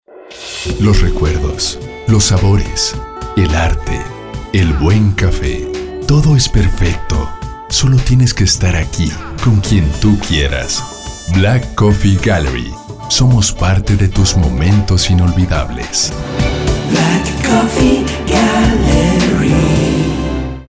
Les copio el archivo con el SPOT de radio, que tendremos al aire a partir
Nombre     : SPOT BLACK COFFE VOZ  MIXTA.mp3